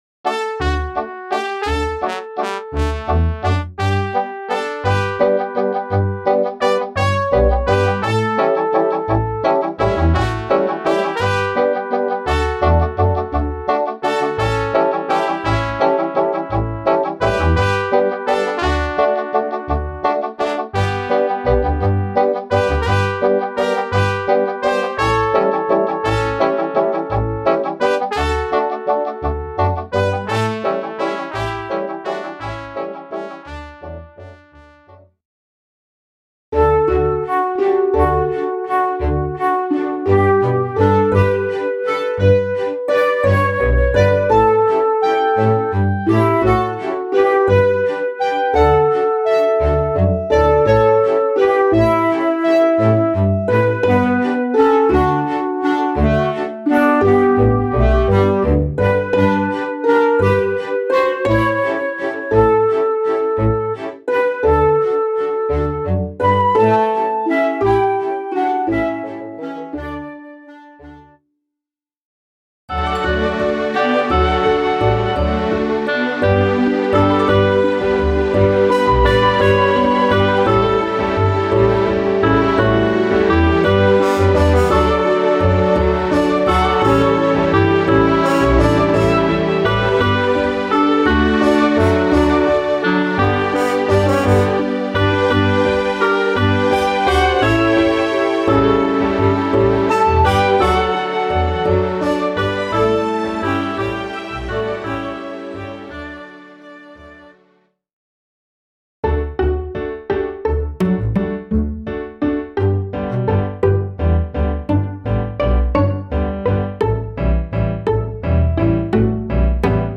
Instrumentalsatz